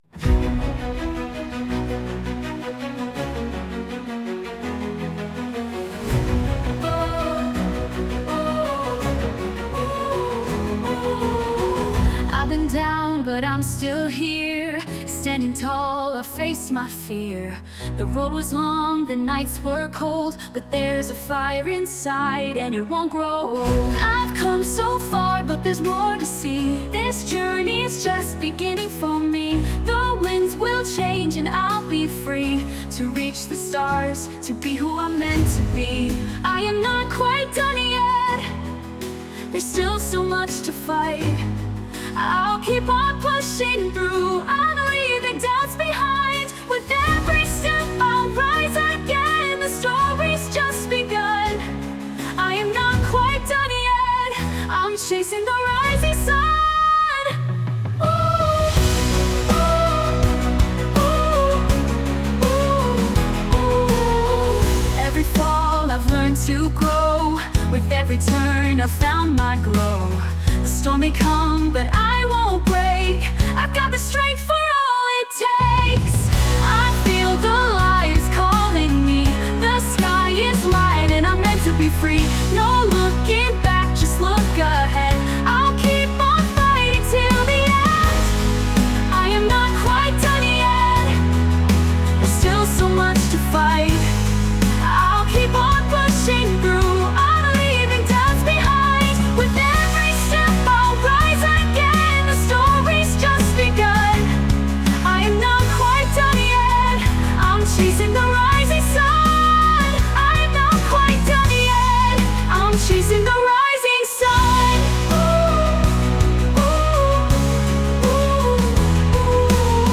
"I Am Not Quite Done Yet" (pop)